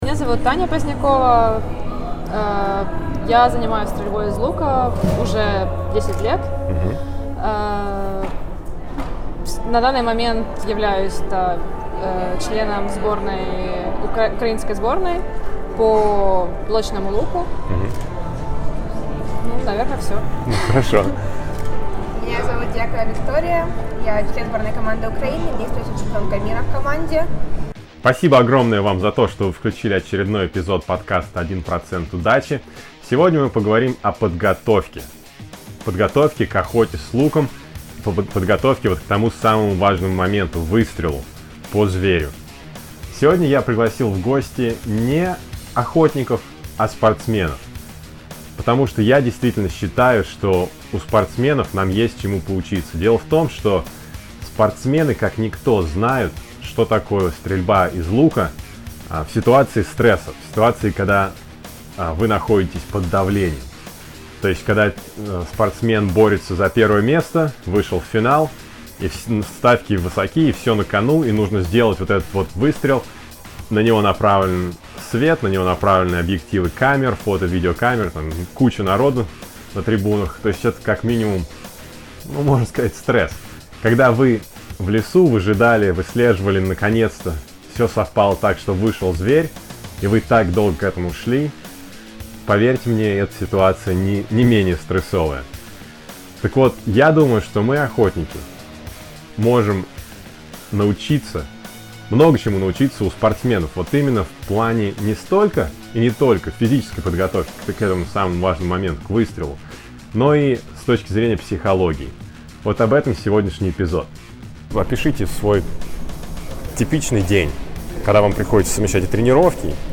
Для записи этого эпизода я встретился с двумя лучницами-спортсменками